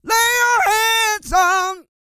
E-SING W 110.wav